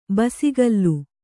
♪ basigallu